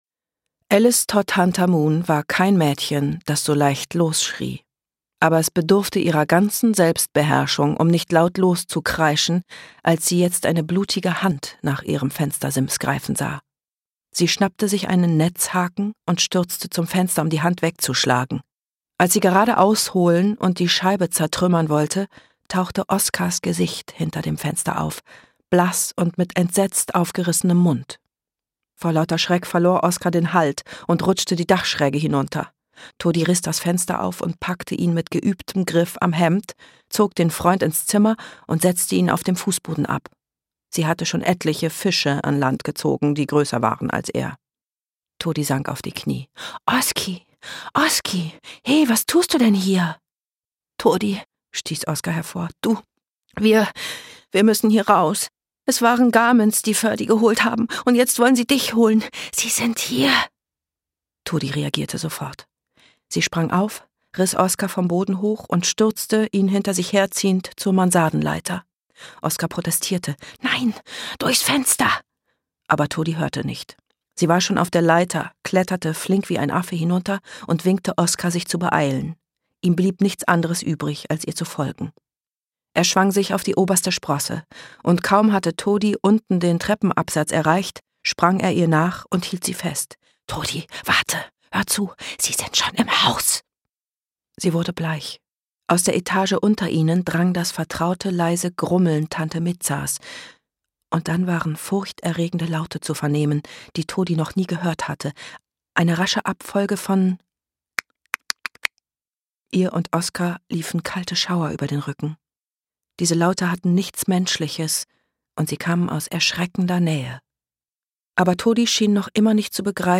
Schlagworte Abenteuer • Fantasy; Kinder-/Jugendliteratur • Hörbuch; Lesung für Kinder/Jugendliche • Magie • Magie; Kinder-/Jugendliteratur • Septimus Heap